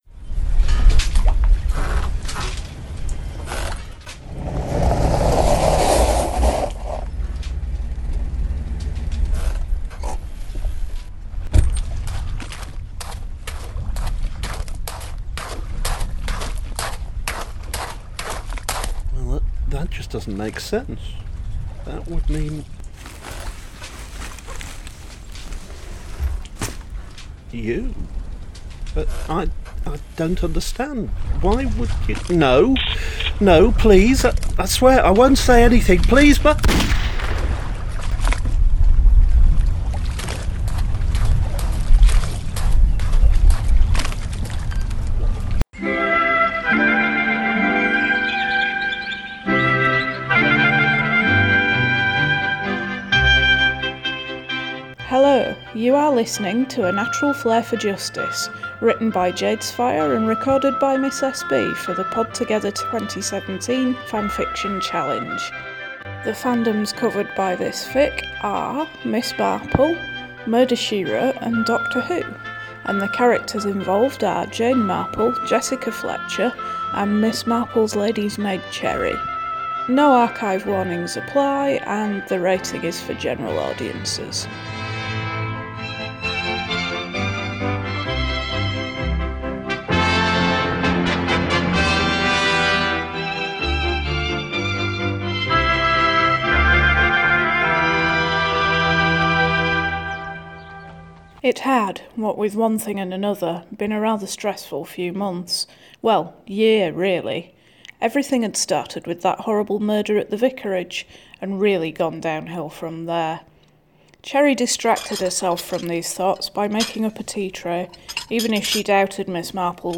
You can play the podfic in the player above, or you can download it directly here.